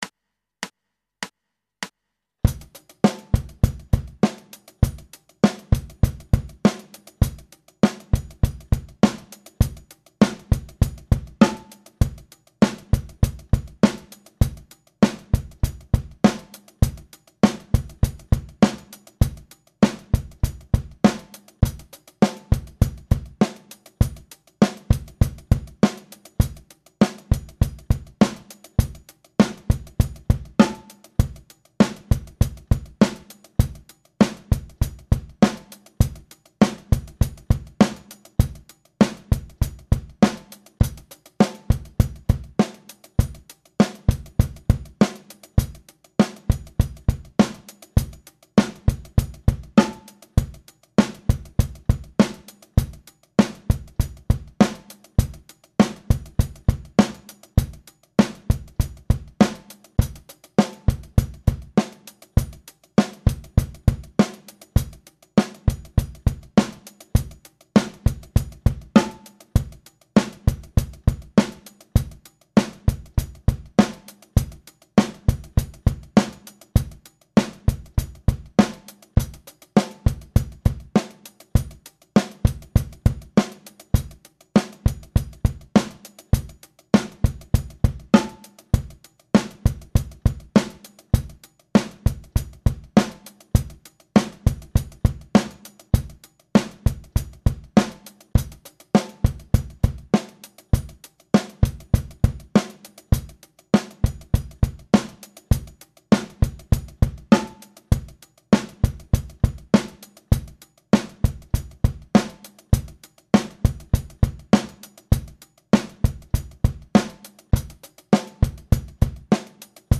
Der Drumgroove!
Hier gibt's den Drumtrack zum Üben (ca. 2MB!)
Slap-Grooves 1_Drumtrack.mp3